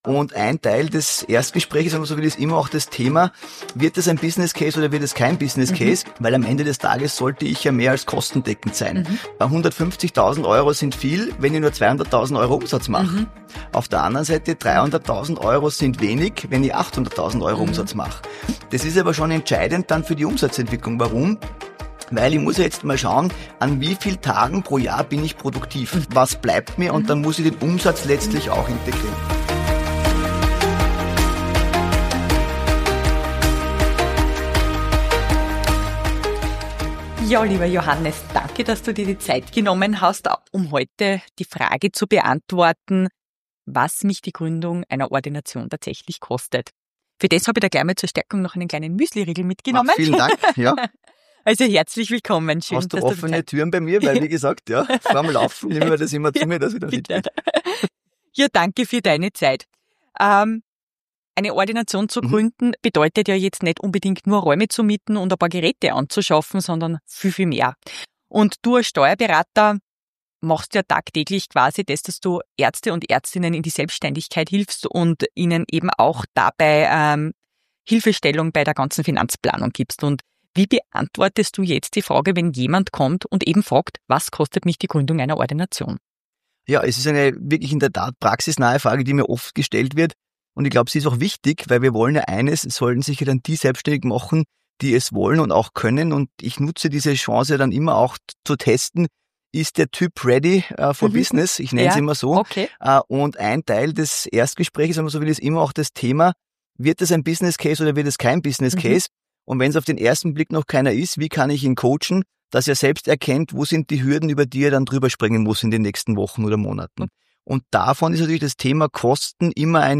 Freut euch auf praxisnahe Tipps, einen Faktencheck rund um Kostenblöcke, Stundensätze und die Rolle der Reserve – humorvoll und verständlich präsentiert!